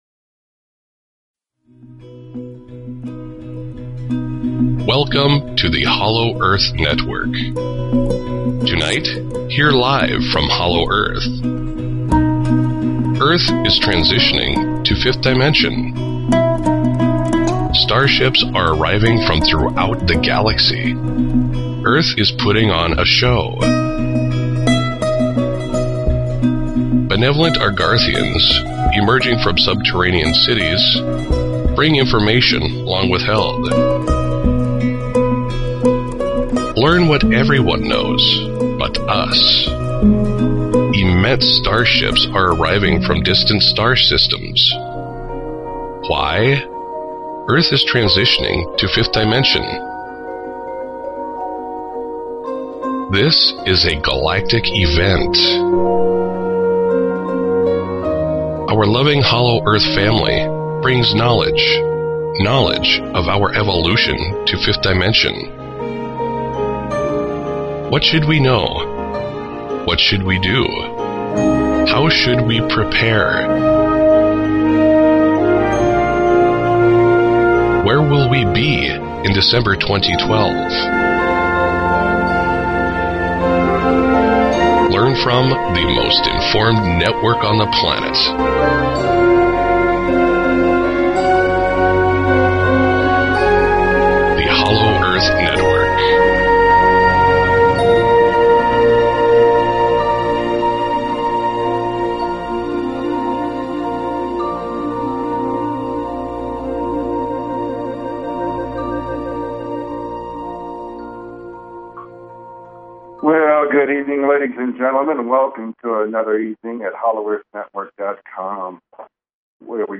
Talk Show Episode, Audio Podcast, Hollow_Earth_Network and Courtesy of BBS Radio on , show guests , about , categorized as